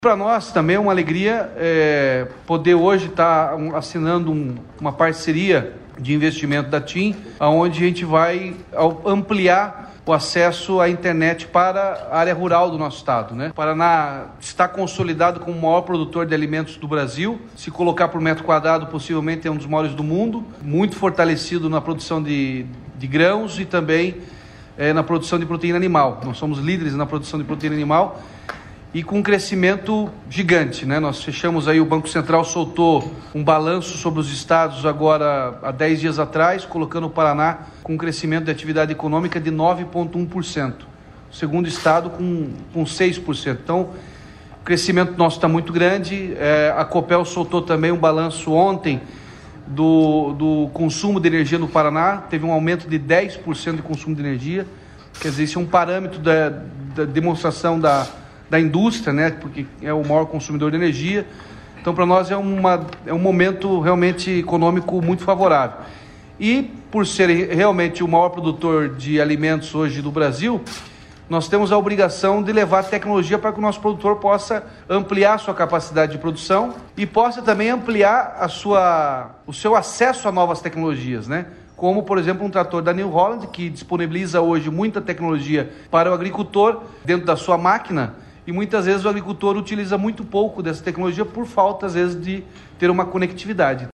Sonora do governador Ratinho Junior sobre o protocolo com a operadora TIM para ampliar a cobertura de internet e telefonia móvel nas áreas rurais do Paraná